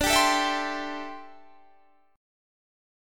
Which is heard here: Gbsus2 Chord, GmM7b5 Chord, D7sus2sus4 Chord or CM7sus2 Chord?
D7sus2sus4 Chord